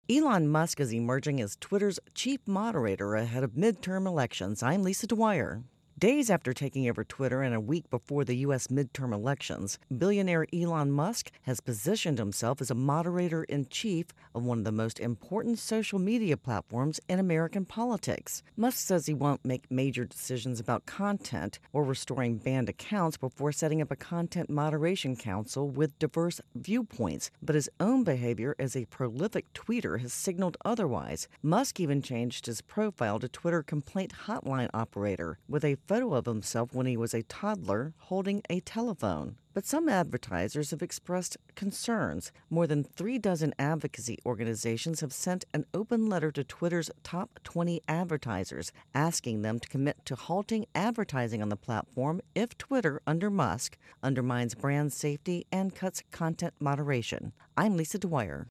reports on Musk Twitter.